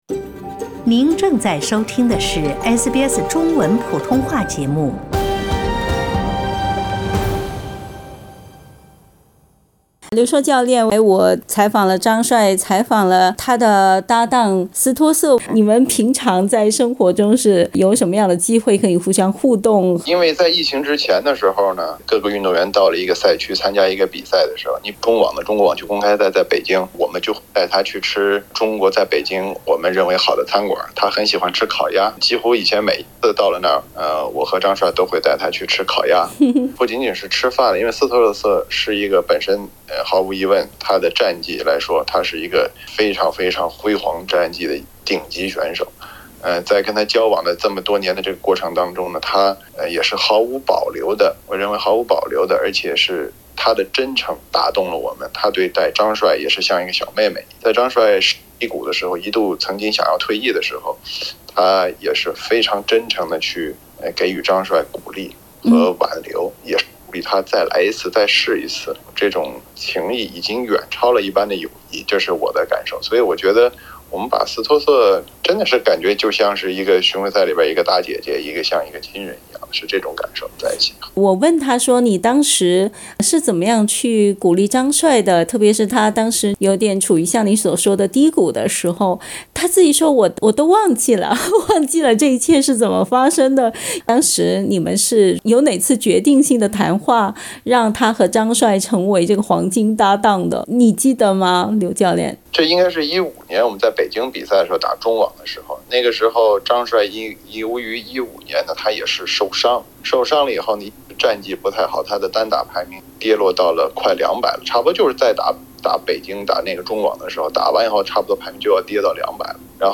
斯托瑟/張帥專訪：我們的合作與友情（下）